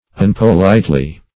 Meaning of unpolitely. unpolitely synonyms, pronunciation, spelling and more from Free Dictionary.